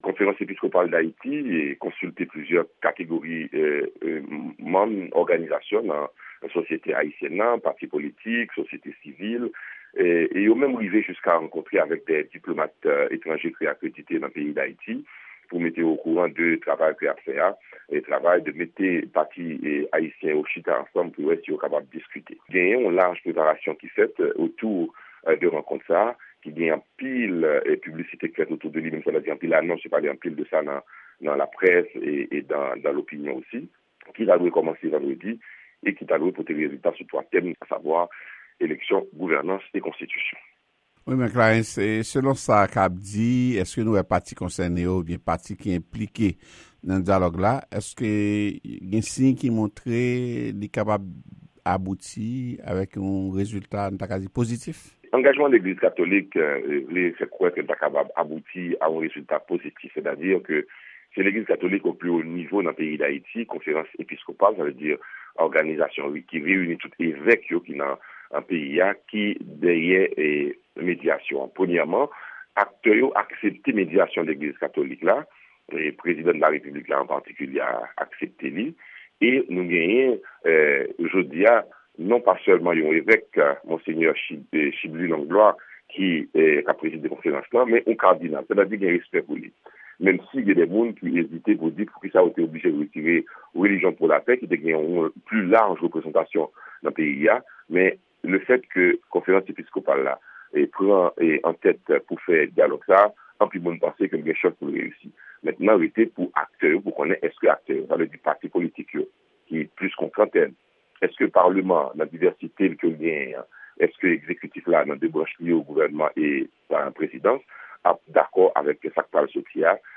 Entèvyou